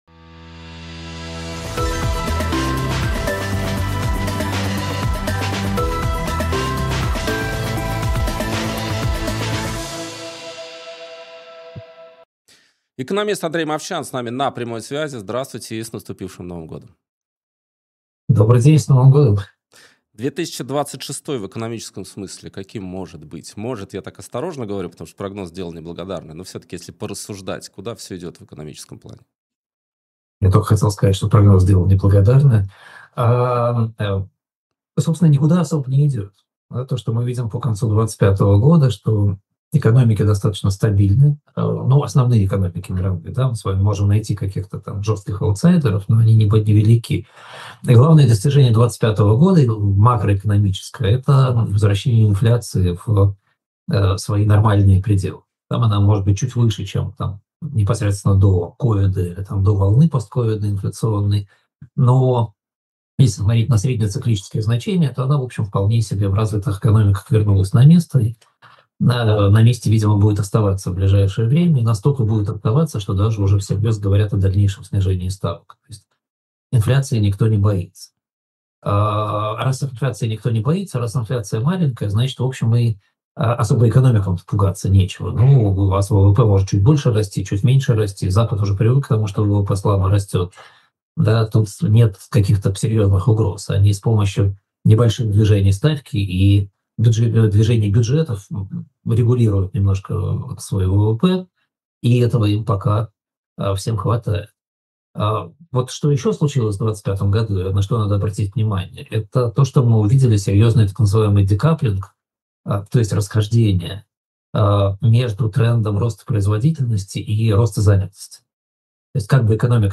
Андрей Мовчан экономист